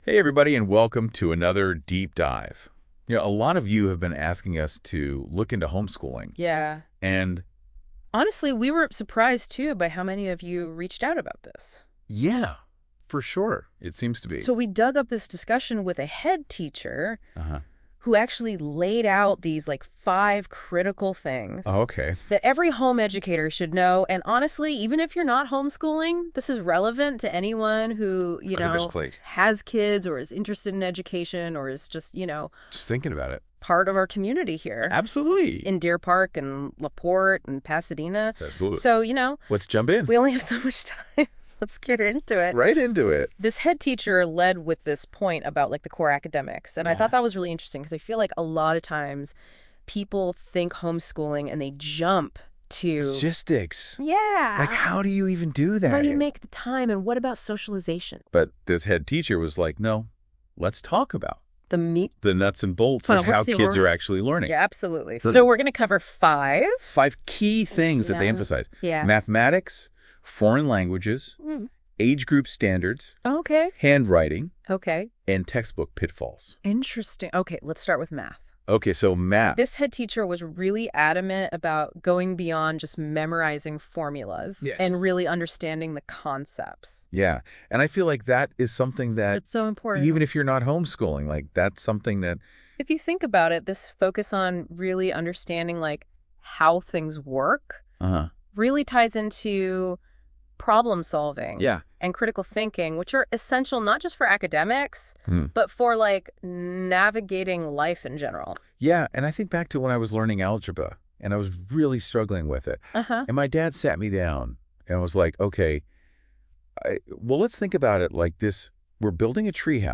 A head teacher outlines five essential areas for parents to consider when homeschooling their children. These considerations include the importance of mathematics and foreign language instruction.